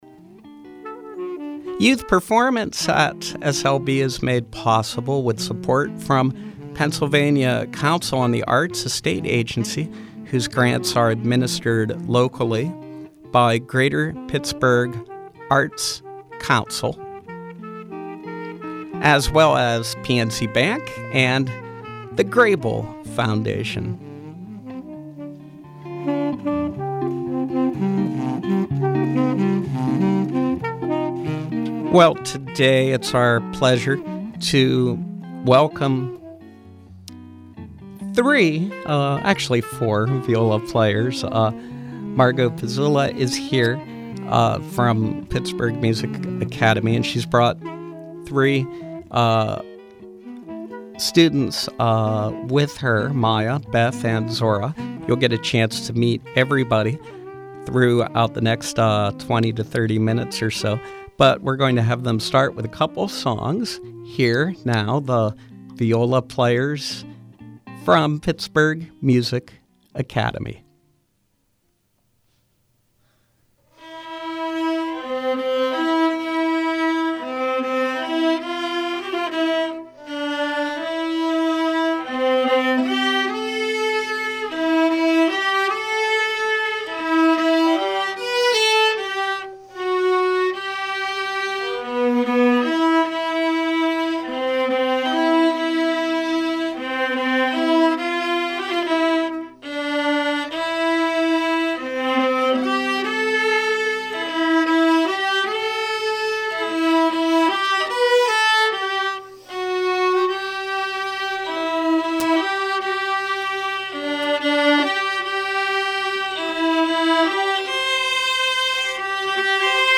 From 1-10-15: Young viola players from Pittsburgh Music Academy.